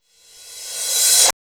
POP CR RVRS.wav